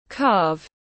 Calve /kɑːvs/